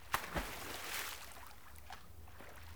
SINGLE SP02L.wav